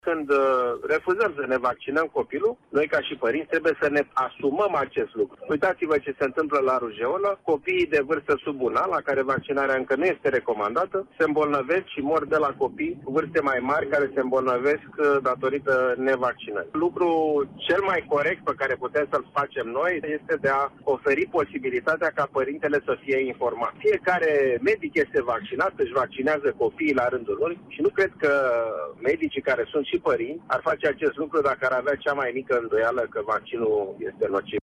Preşedintele Societăţii Române de Microbiologie, Alexandru Rafila, a declarat la Radio Iaşi că părinţii ar trebui să fie informaţi din surse autorizate – de la medici sau specialişti în măsură să le explice care sunt urmările refuzului de a-i imuniza pe cei mici: